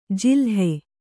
♪ jilhe